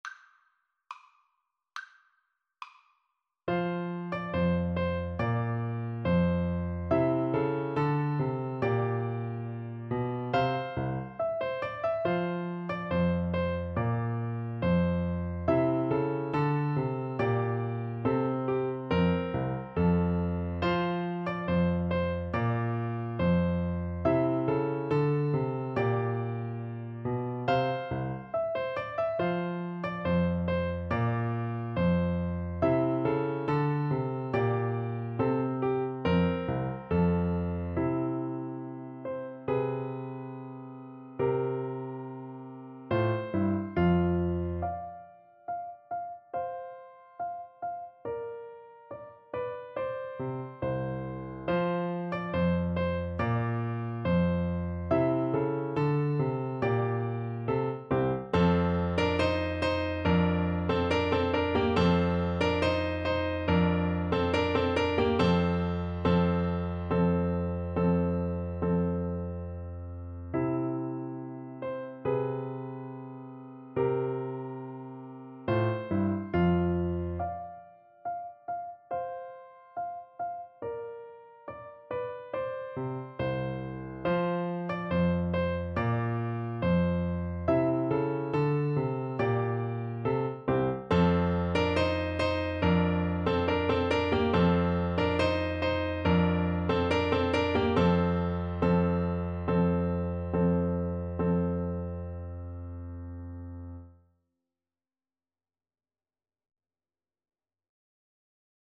F major (Sounding Pitch) (View more F major Music for Flute )
2/4 (View more 2/4 Music)
Classical (View more Classical Flute Music)